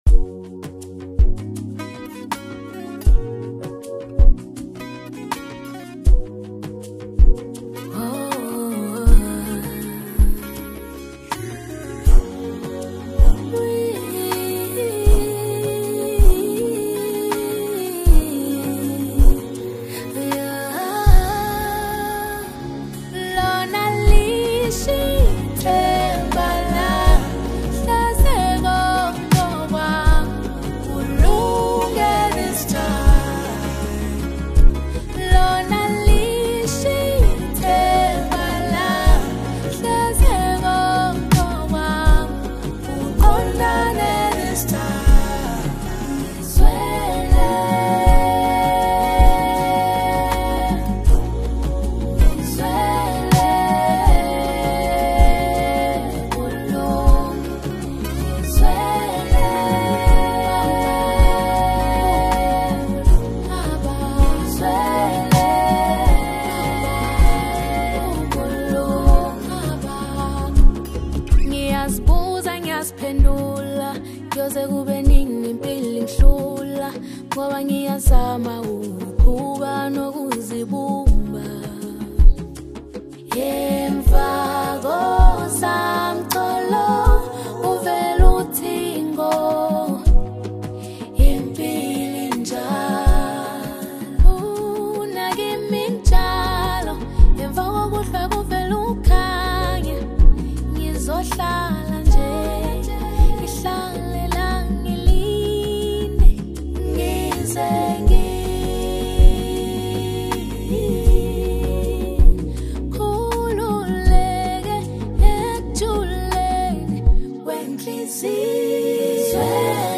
AFRO-POP Apr 07, 2026